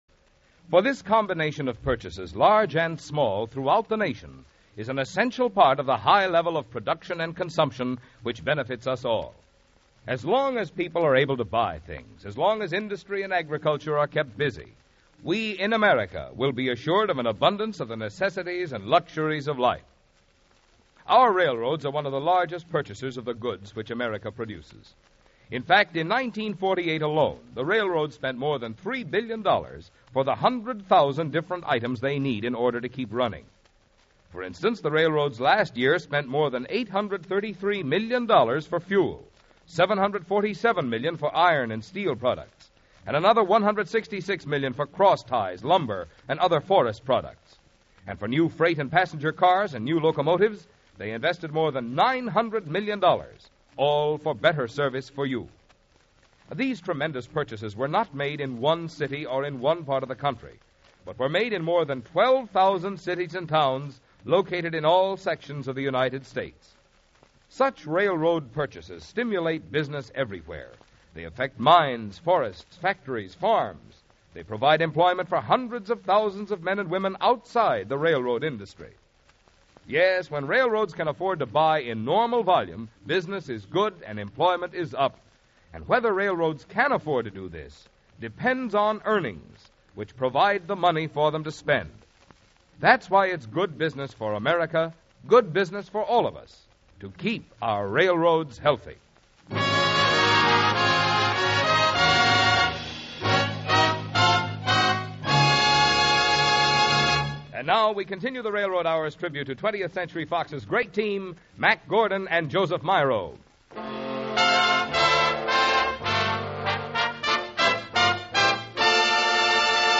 radio series that aired musical dramas and comedies